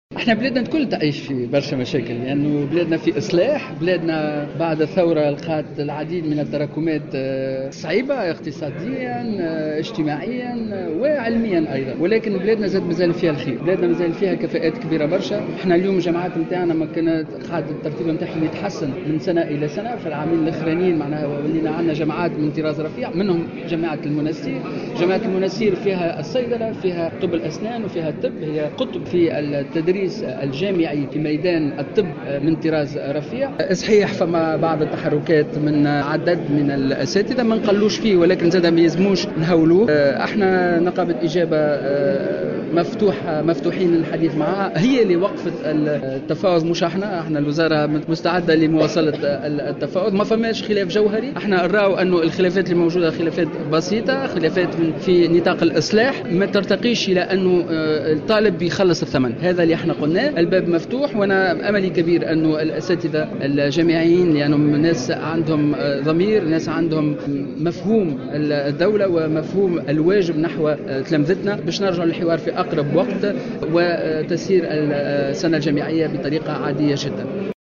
وفي ما يتعلّق باحتجاجات نقابة إجابة، قال الوزير في تصريح لمراسل "الجوهرة اف أم" على هامش مؤتمر طبي للنقابة الوطنية لأطباء القطاع الخاص فرع المنستير، إن باب التفاوض مفتوح مع نقابة إجابة معتبرا الخلافات بسيطة وليست جوهرية، داعيا الاساتذة إلى الحوار في أقرب وقت، وفق تعبيره.